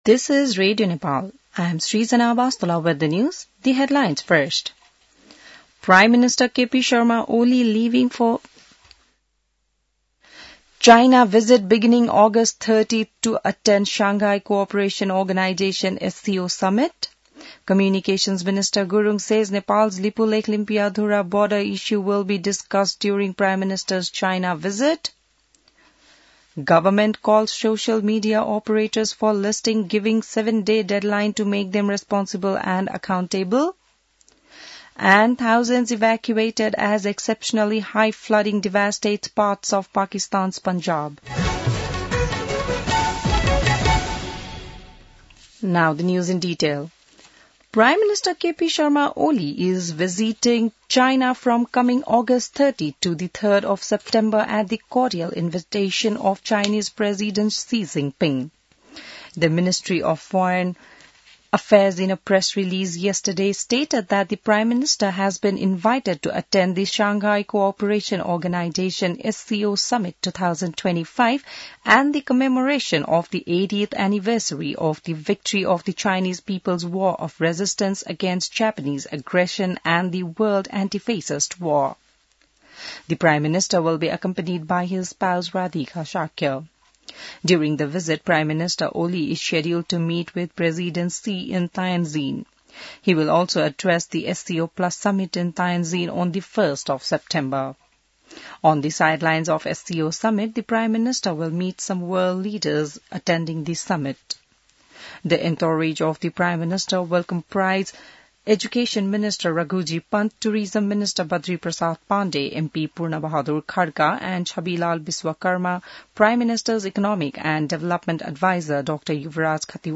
बिहान ८ बजेको अङ्ग्रेजी समाचार : १२ भदौ , २०८२